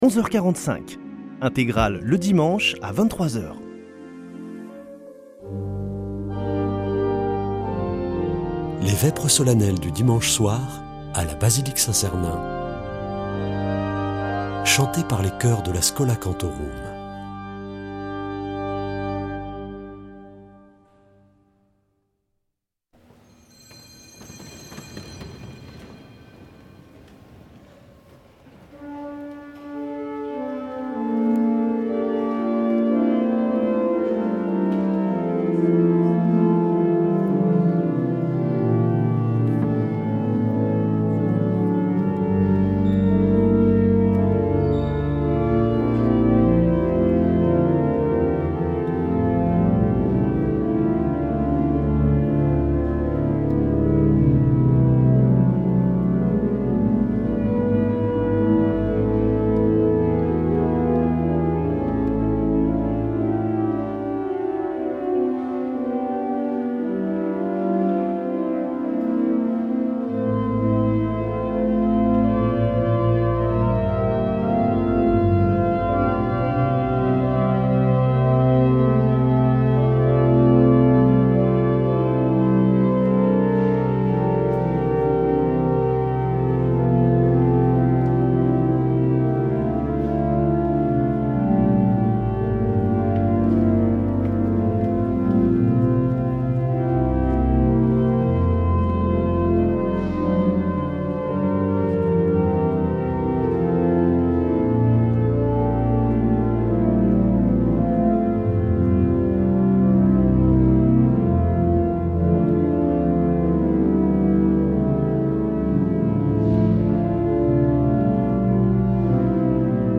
Vêpres de Saint Sernin du 10 mars
Une émission présentée par Schola Saint Sernin Chanteurs